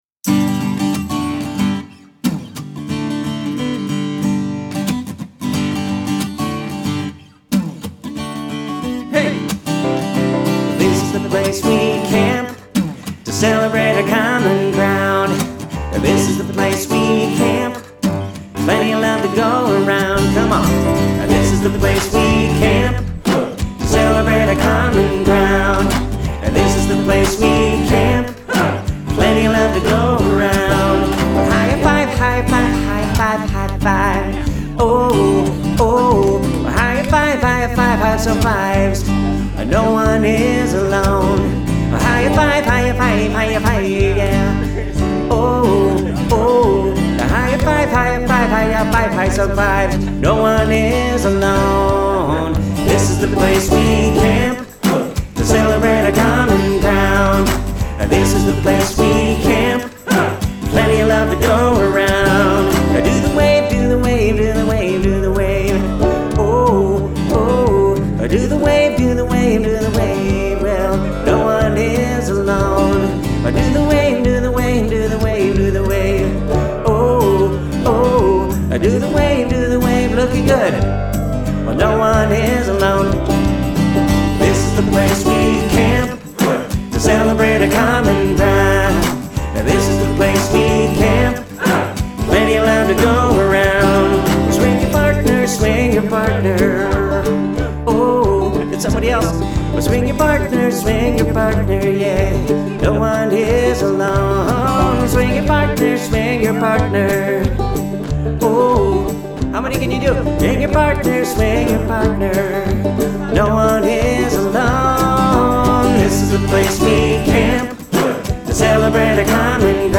intergenerational camp song album